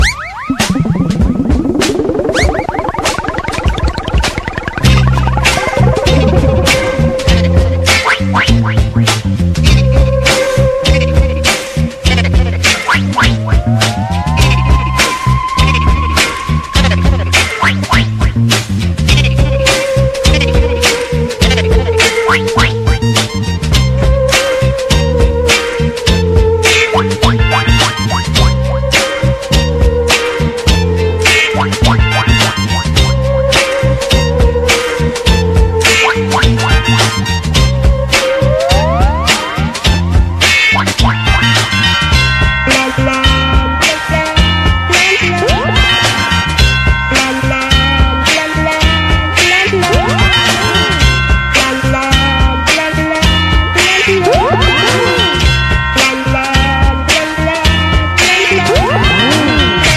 BREAKBEATS/HOUSE / GROUND BEAT / UK SOUL